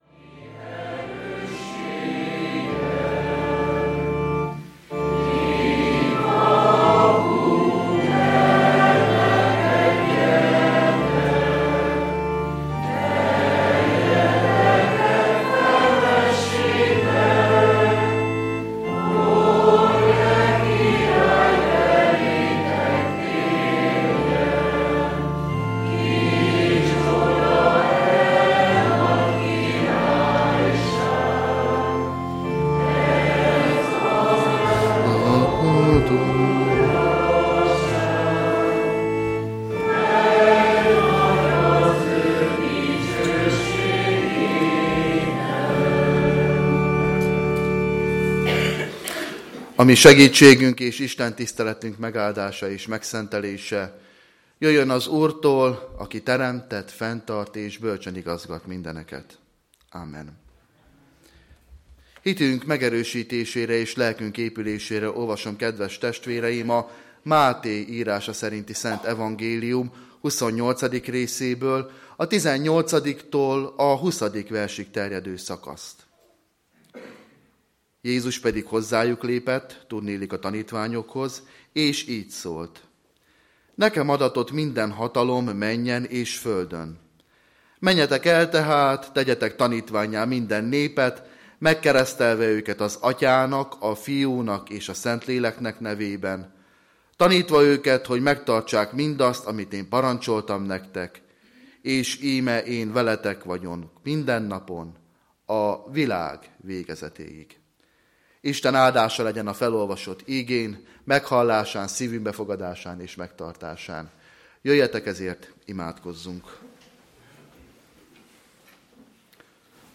Lekció